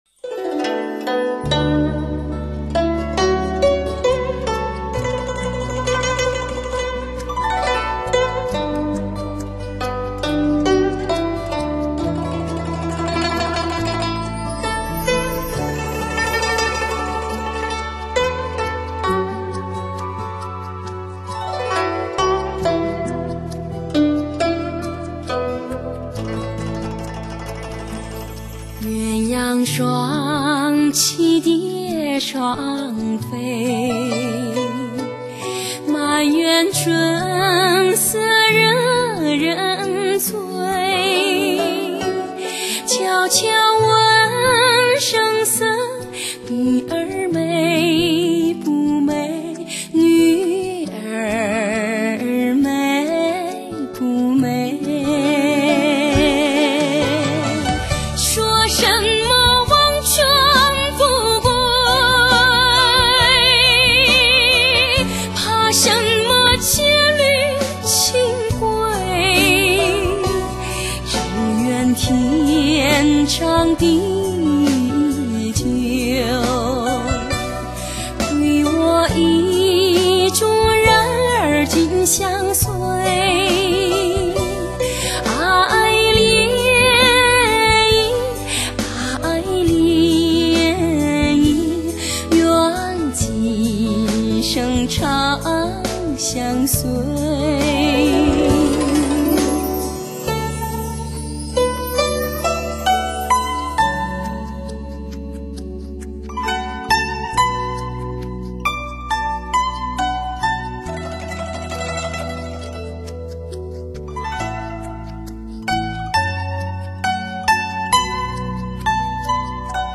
（试听为低品质WMA，下载为320K/MP3）